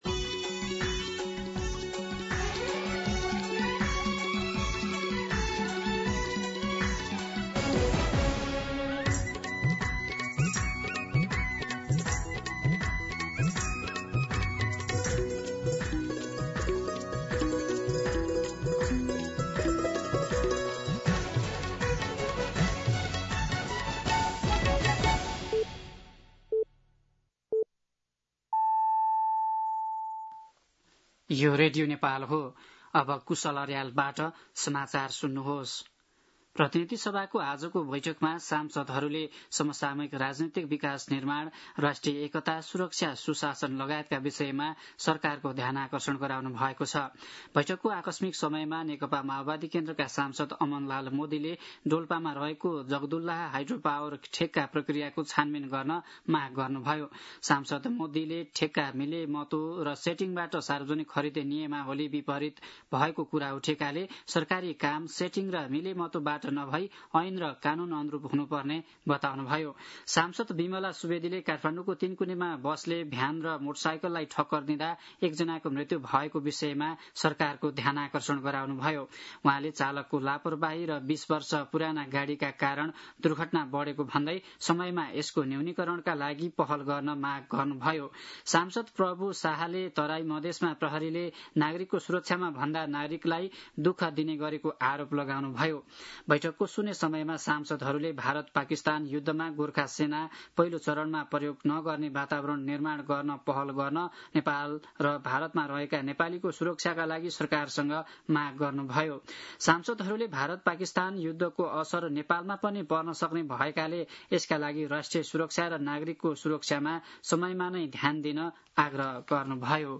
दिउँसो ४ बजेको नेपाली समाचार : २५ वैशाख , २०८२
4-pm-Nepali-News-1.mp3